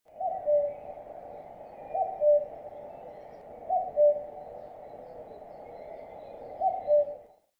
На этой странице собраны натуральные звуки кукушки, которые можно скачать или слушать онлайн.
Звук кукушки в лесу